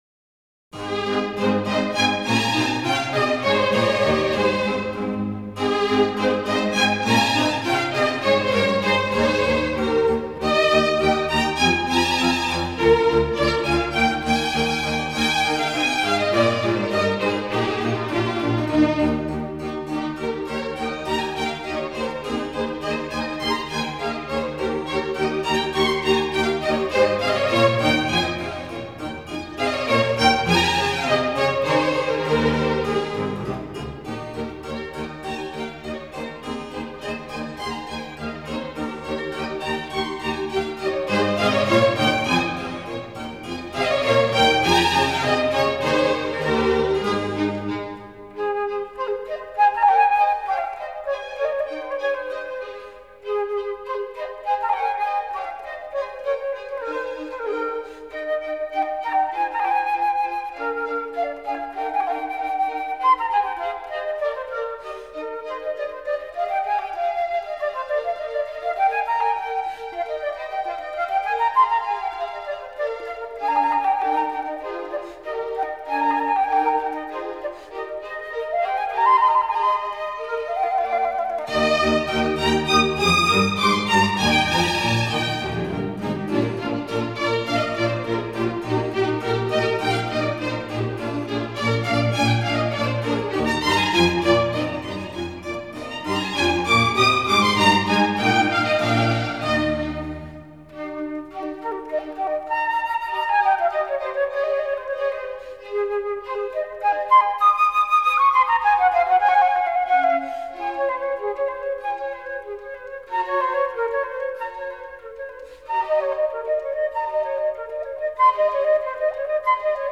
Leningrad Ensemble Of Ancient Music
flute) 1. Allegro Non Molto 2. Andante 3. Allegro